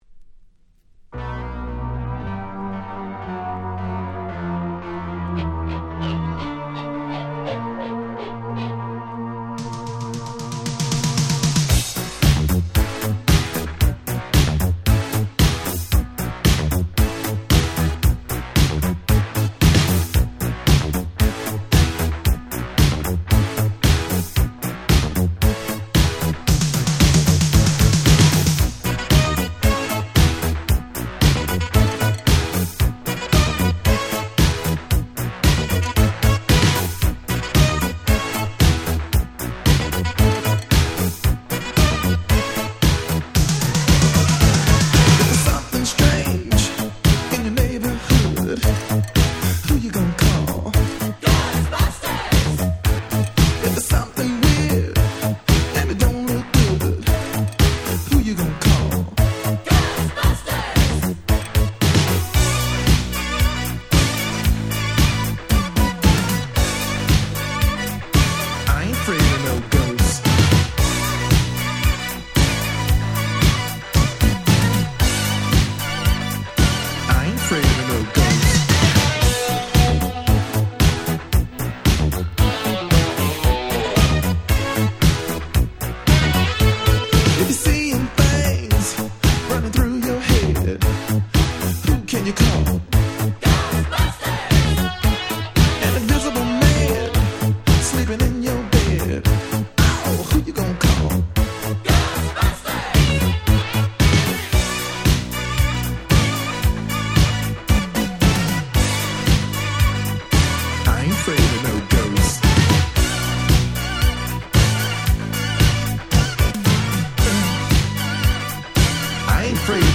84' Super Hit Disco !!